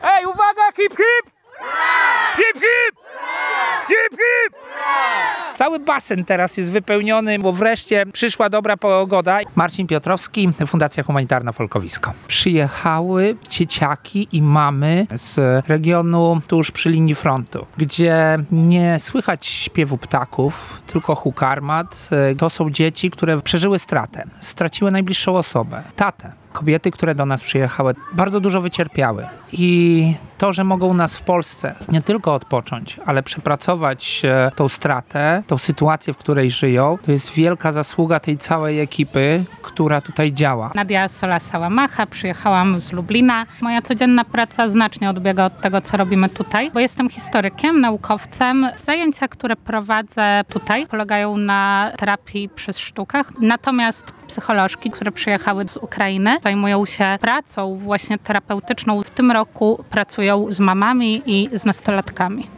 Obóz wytchnieniowo-terapeutyczny w pensjonacie w Kowalówce w powiecie lubaczowskim to czas radości i beztroski. Relacja